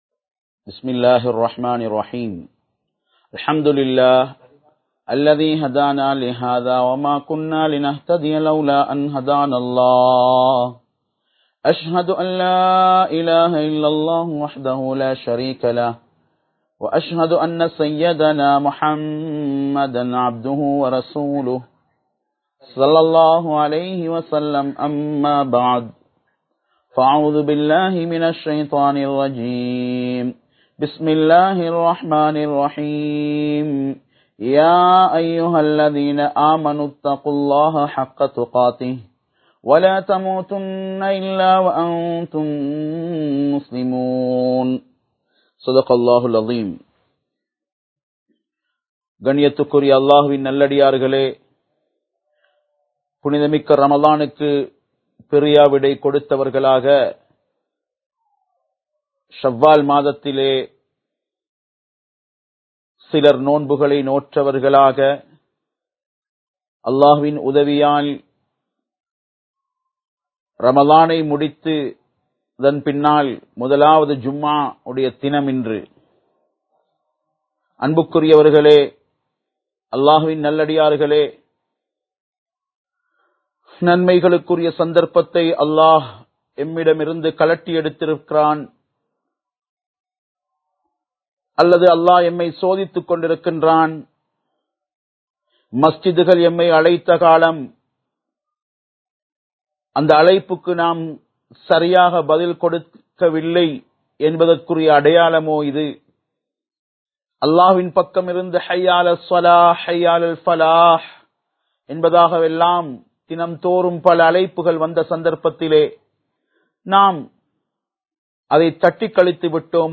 படைத்தவனின் கட்டளைகளை மீறாதீர்கள் (Dont Transgress the commandmends of Allah) | Audio Bayans | All Ceylon Muslim Youth Community | Addalaichenai
Live Stream